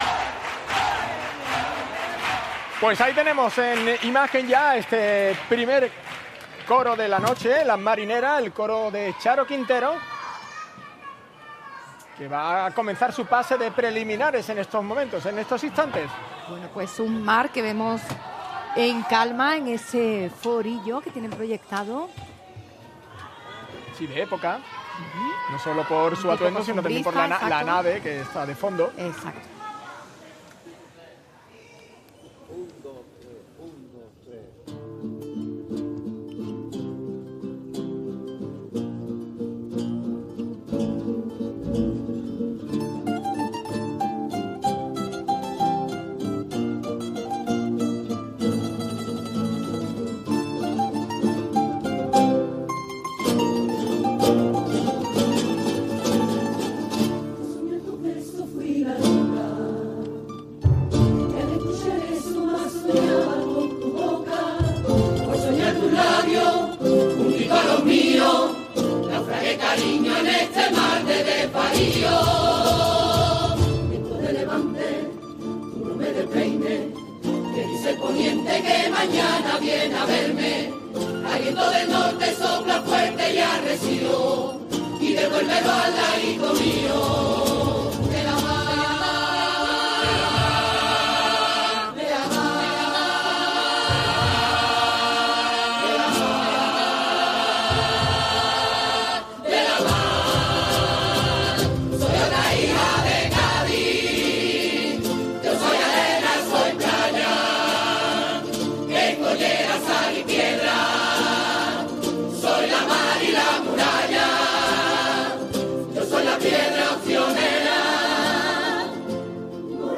en la fase preliminares del COAC Carnaval de Cádiz 2026